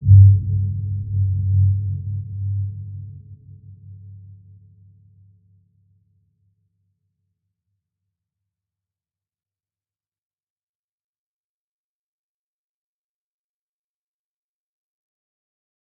Dark-Soft-Impact-G2-p.wav